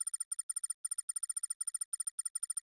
intro-typing.mp3